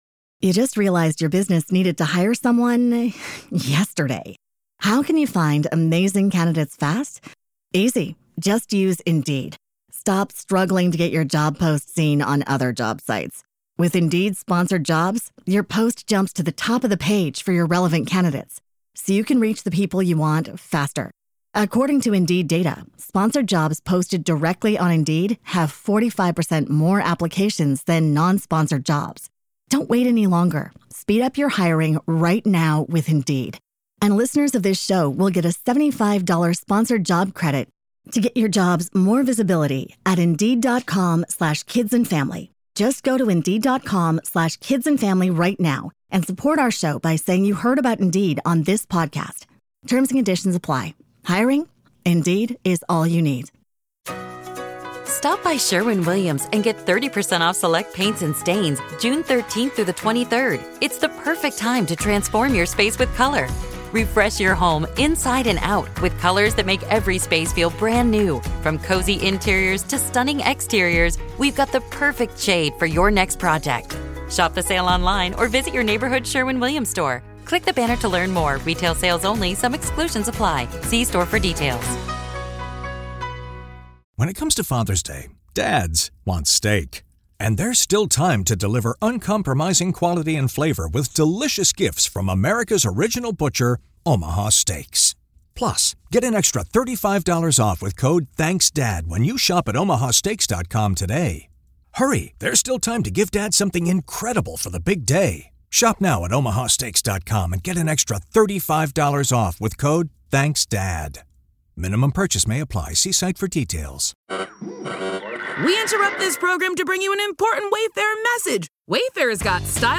we sit down with paranormal researcher